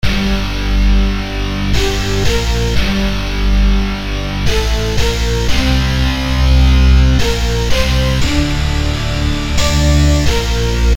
硬电吉他
描述：他是一个很好的硬摇滚或hiphop摇滚节拍的循环。
Tag: 88 bpm Rock Loops Guitar Electric Loops 1.84 MB wav Key : Unknown